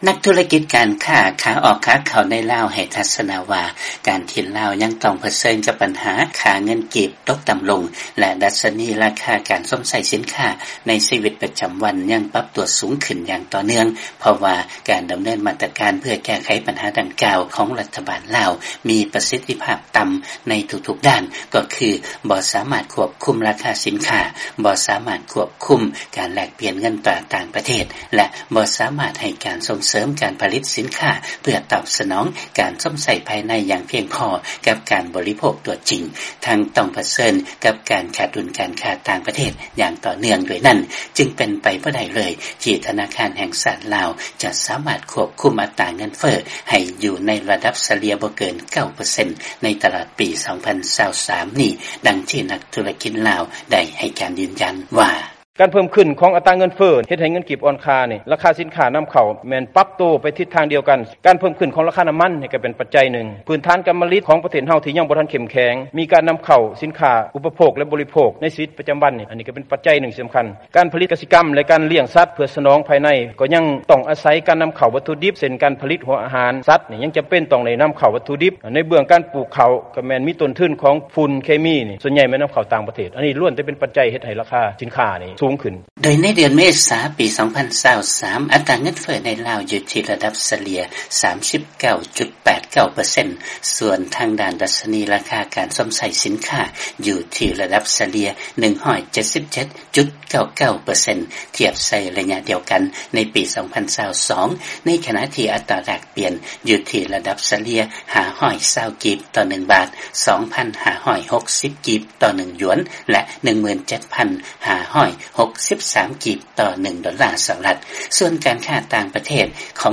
ຟັງລາຍງານ ທະນາຄານແຫ່ງຊາດລາວ ຈະຄວບຄຸມອັດຕາເງິນເຟີ້ປະຈຳປີ ໃຫ້ຢູ່ໃນລະດັບ ບໍ່ເກີນກວ່າ 9 ເປີເຊັນ ໃນປີ 2023